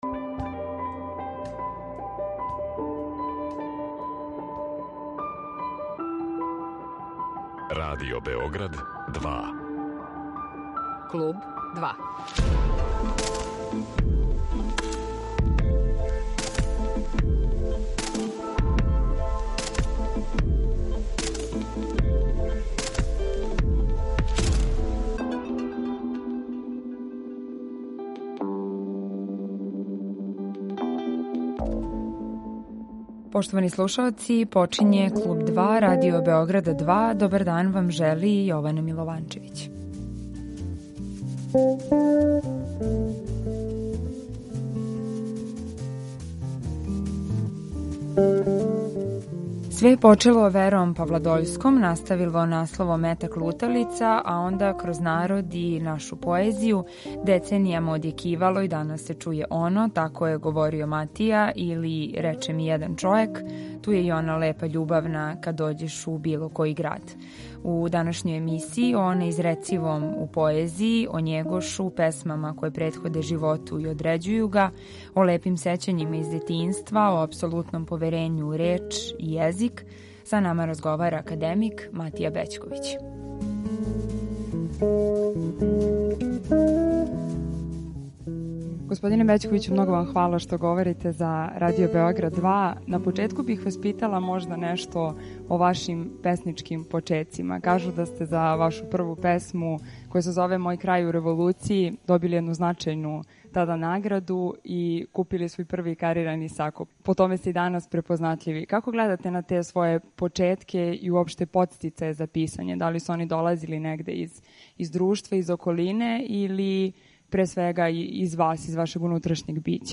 Гост Клуба 2 је академик Матија Бећковић.